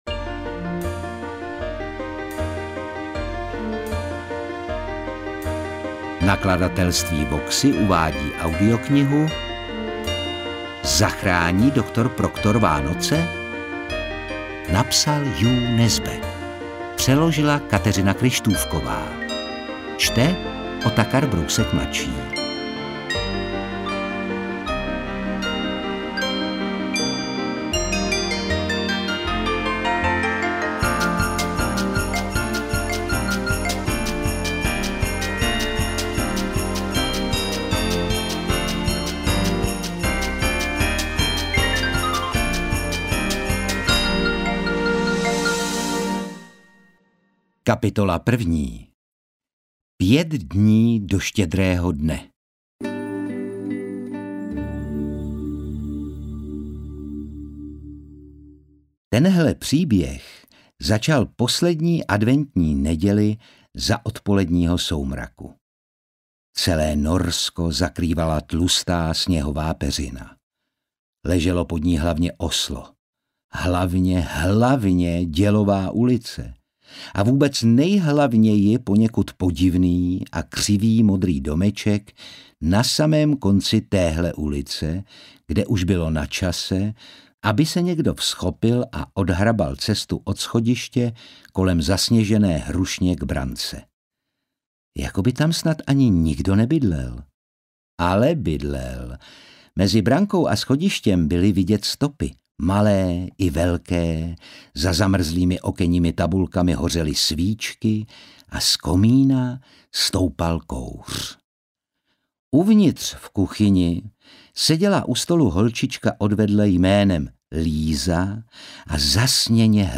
Interpret:  Otakar Brousek ml.
AudioKniha ke stažení, 19 x mp3, délka 5 hod. 59 min., velikost 327,0 MB, česky